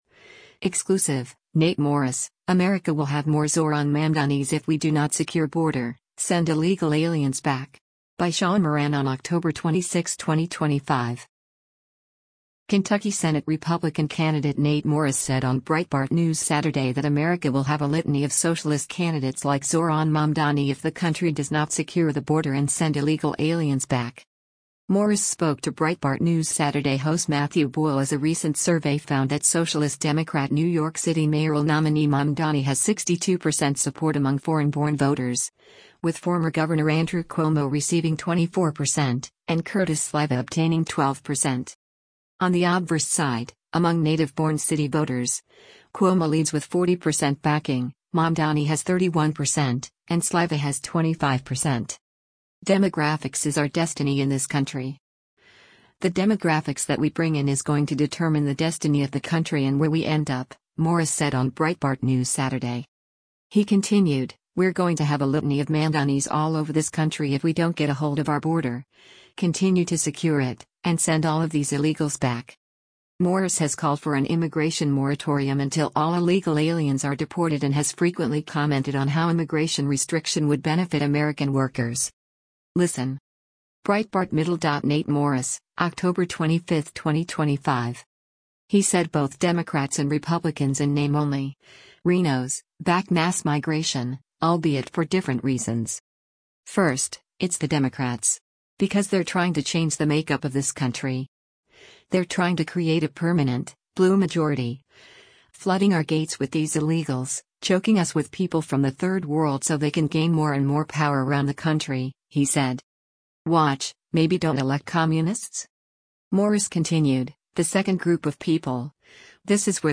Breitbart News Saturday airs on SiriusXM Patriot 125 from 10:00 a.m. to 1:00 p.m. Eastern.